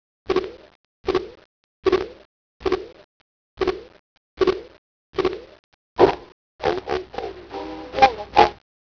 You see, after I walked for a little while a wispy green thing briefly appeared... and shortly after that a siren started screaming, pushing me a few inches back every time it rang out.  Despite my attempts to hold my ground, I was eventually shoved back to the start, dumped into an invisible pool of water, and given a solid red screen while a familiar voice shouted, "Ho ho ho, Green Giant!"